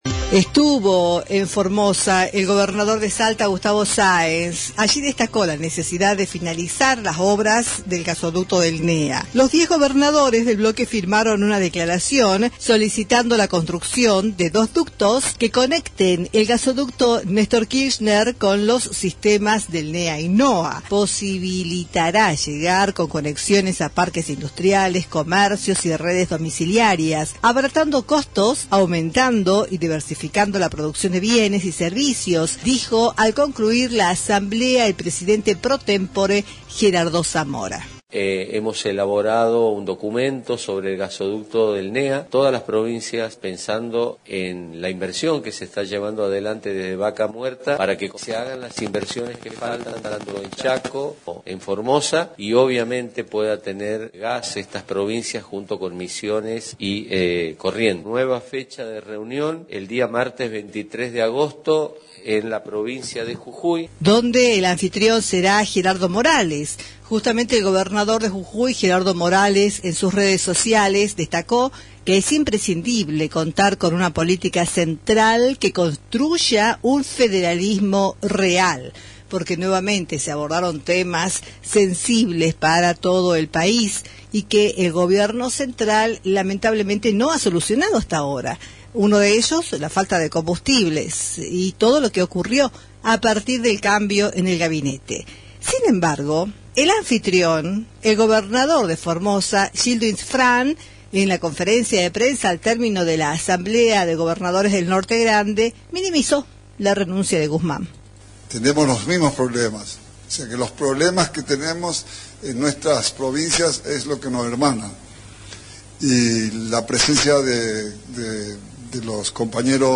En la conferencia de prensa al término de la asamblea, Gildo Insfrán minimizó la renuncia del ex ministro de Economía Martín Guzmán: "No pasó nada, ocurrió como en cualquier Gobierno, un ministro que se va y bueno, hay que reponerlo y esto ya está".
Informe